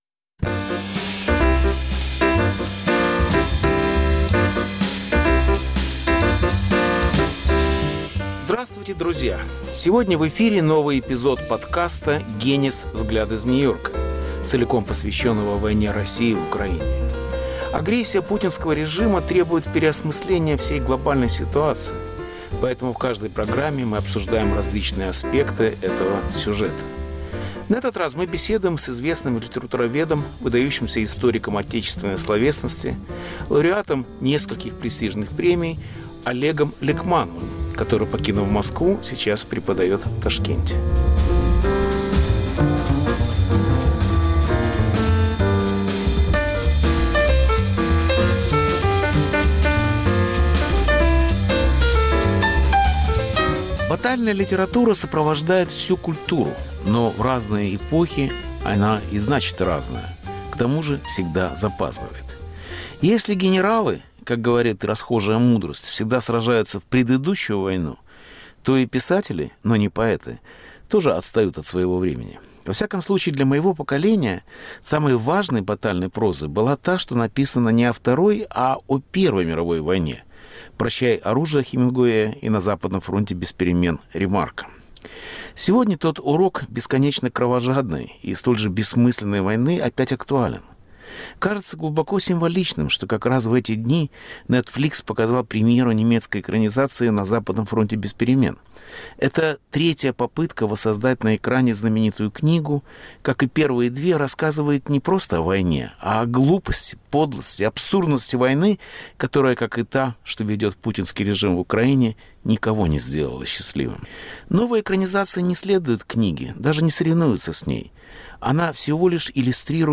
Повтор эфира от 06 ноября 2022 года.